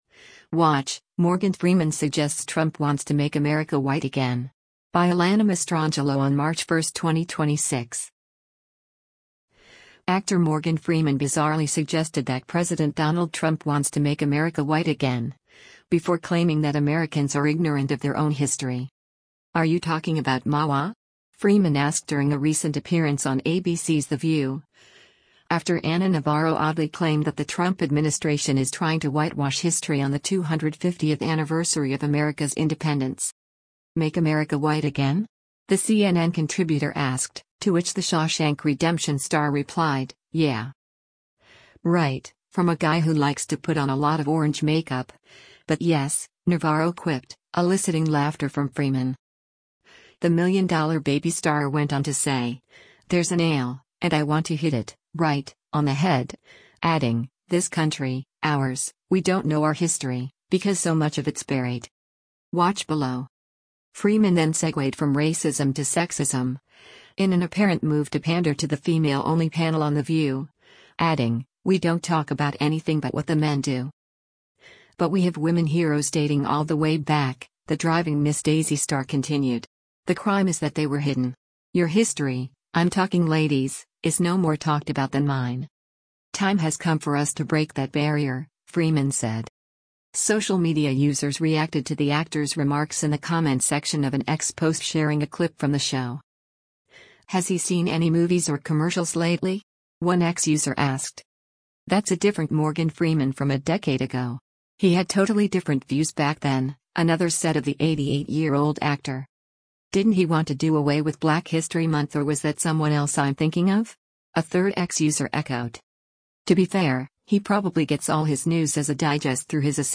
“Right, from a guy who likes to put on a lot of orange makeup, but yes,” Navarro quipped, eliciting laughter from Freeman.